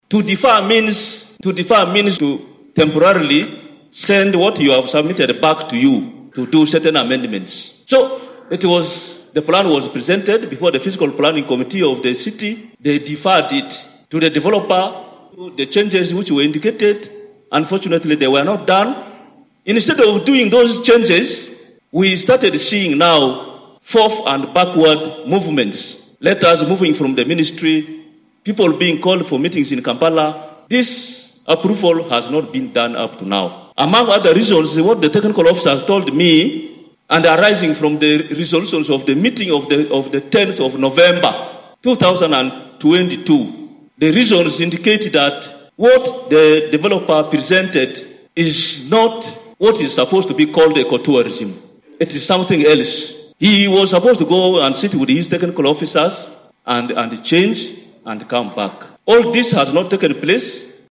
Wadri Sam Nyakua, the Mayor of Arua City, echoed the sentiment that the development plan was deferred for amendments. This implies that the city administration is not outright opposing the project but seeks modifications to address concerns raised during the planning stage.